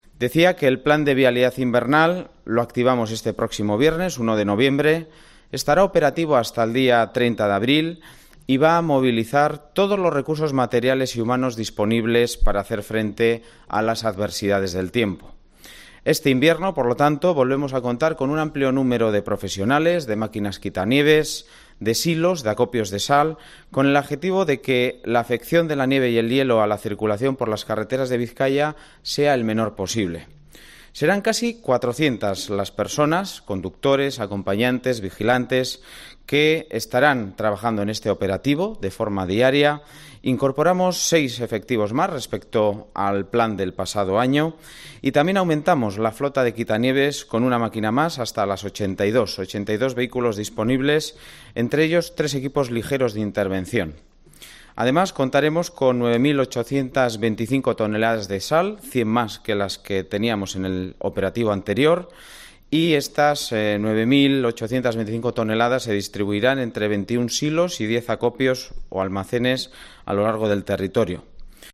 Imanol Pradales, diputado de infraestrcuturas